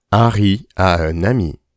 Les dictées du groupes CP :